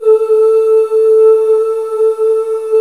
Index of /90_sSampleCDs/Club-50 - Foundations Roland/VOX_xMaleOoz&Ahz/VOX_xMale Ooz 1S